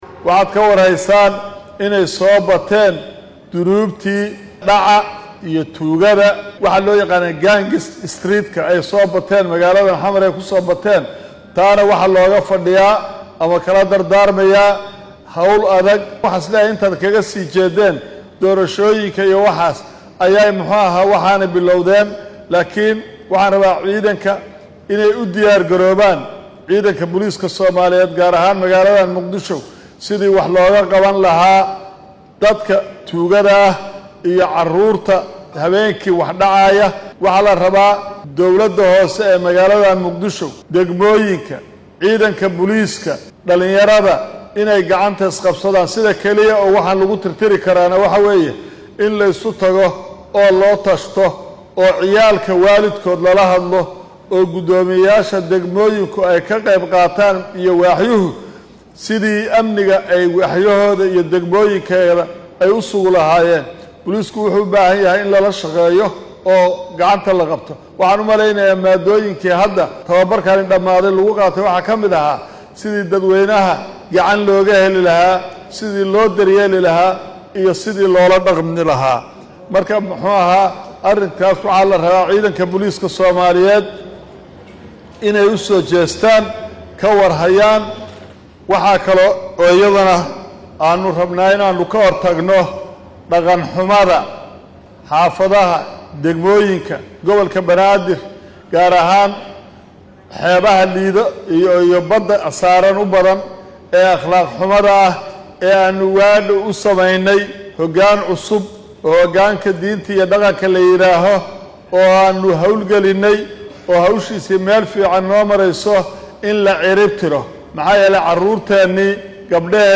Taliyaha ciidanka booliska Soomaaliya Cabdi Xasan Maxamad Xijaar oo ka hadlayay kulan ciidamo tababaro loogu soo xiray ayaa sheegay in intii lagu mashquulsanaa doorashadii soo dhammaatay ee Soomaaliya ay soo bateen dhallinyaradii dhaca iyo dhibaatada ka gaysaneysay caasimadda Muqdisho.